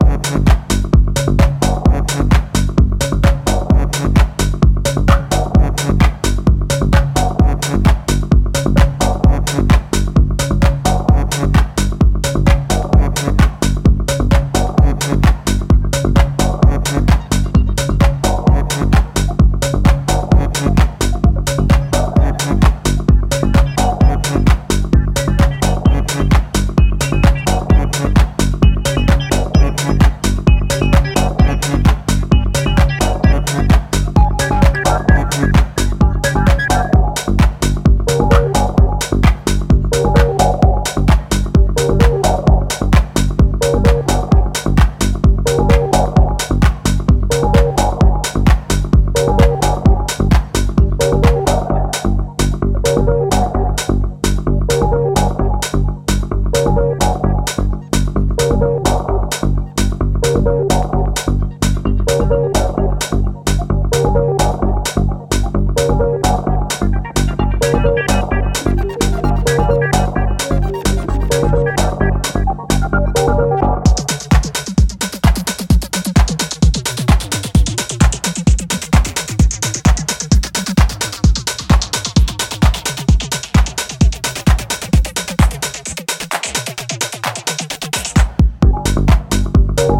弾性高いベースラインと主張の強い裏打ちハイハットでピークタイムへと助走を付ける